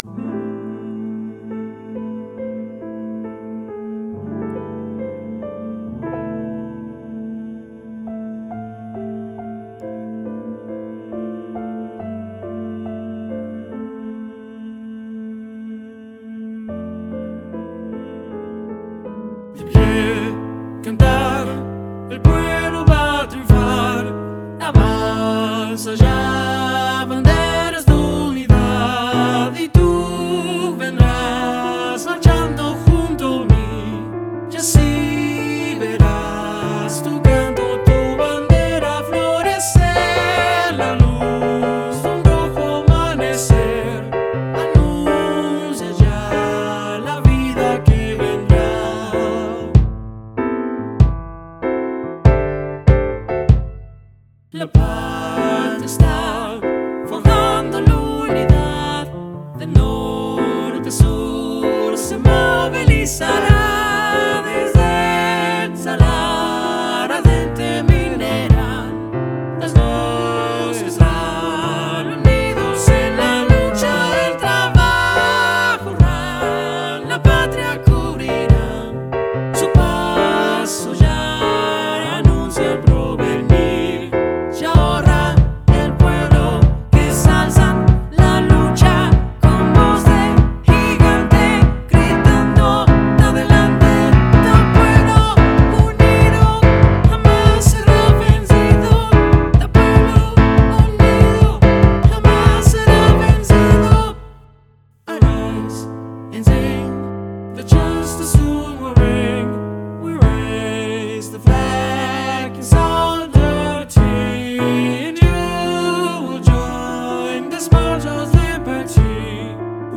SAB + Piano (opt. percussion) 4’00”
SAB, Piano, opt. Percussion